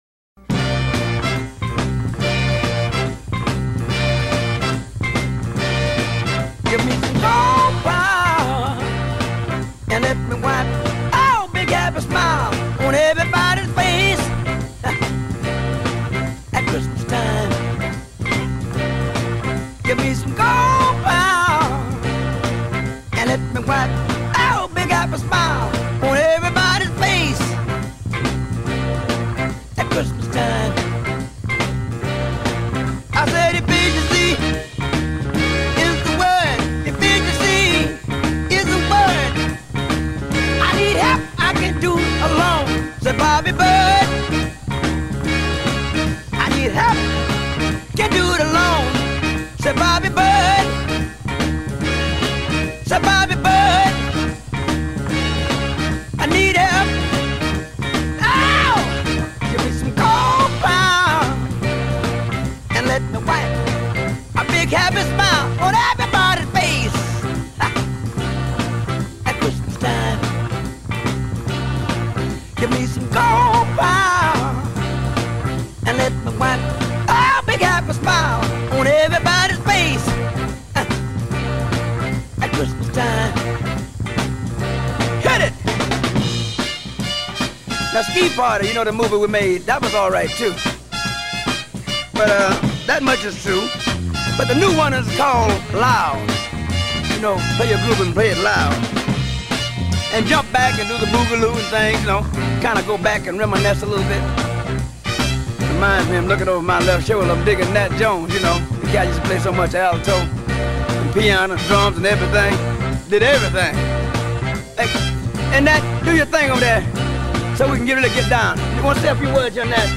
Christmas song